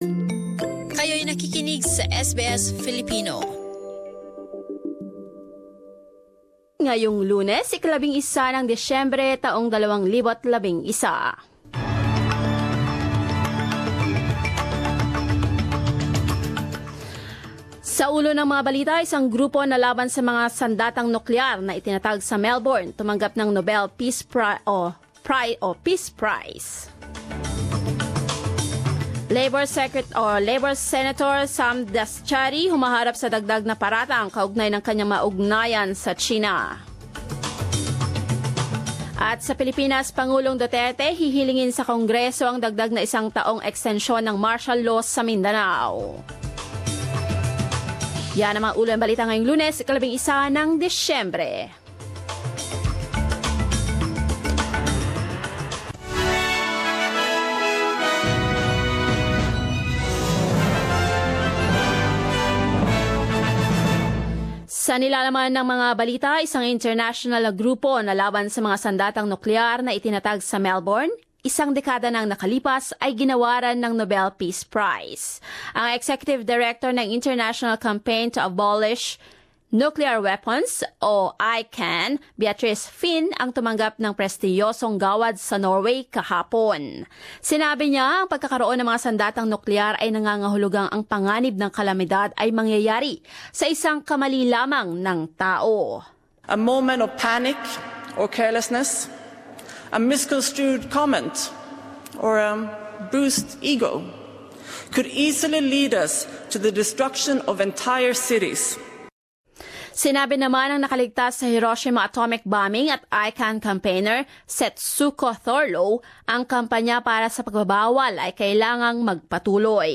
10 am News Bulletin in Filipino 11 December 2017, Monday